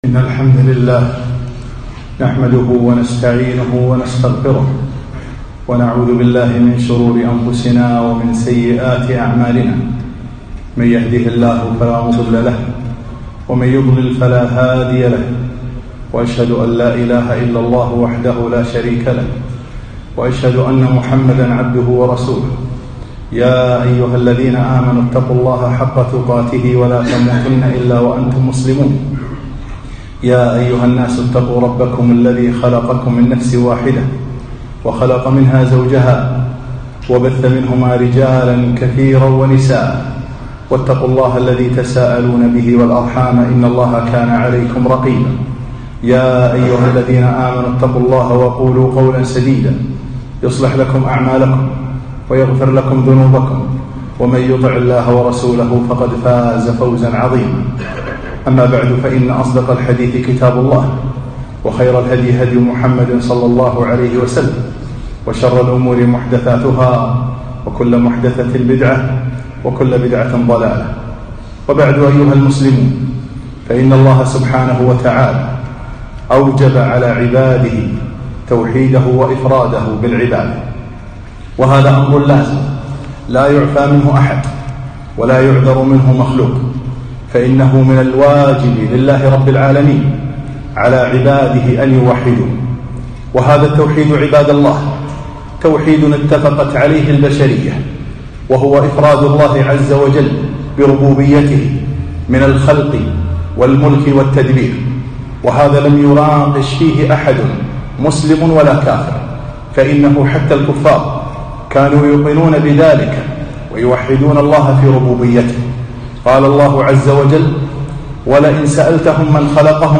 خطبة - التوحيد